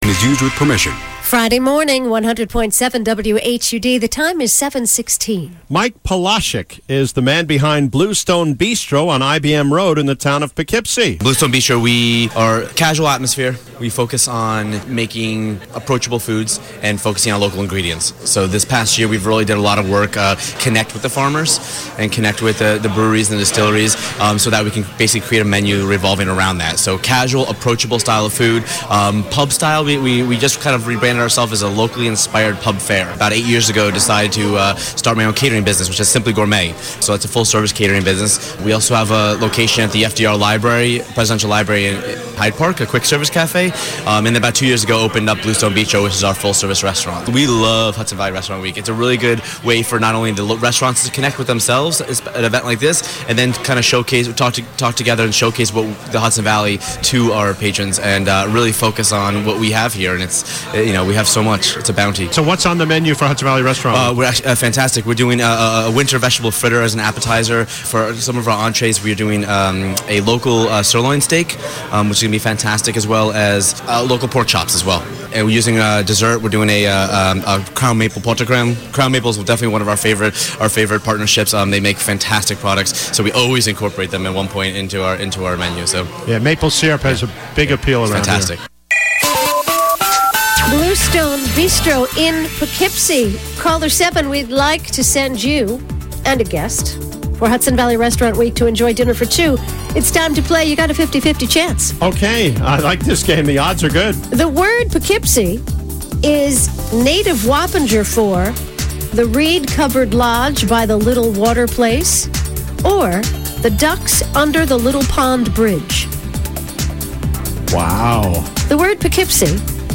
Hudson Valley Restaurant Week Interview-Bluestone Bistro